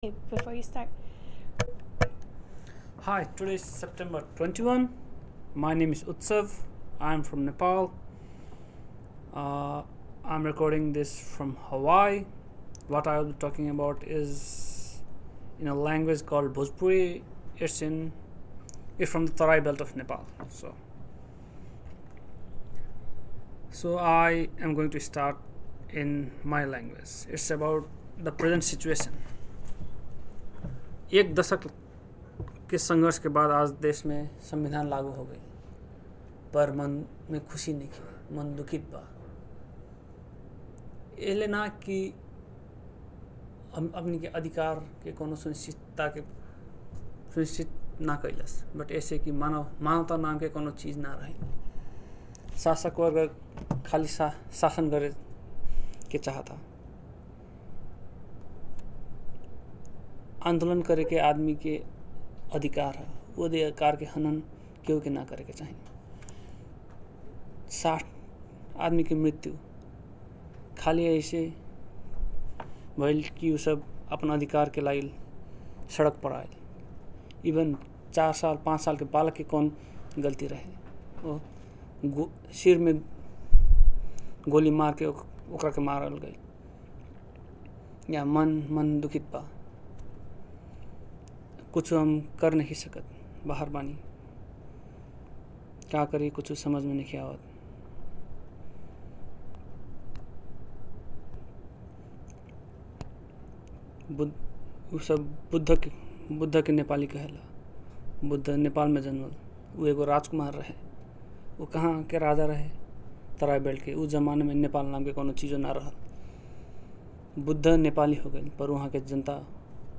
• Story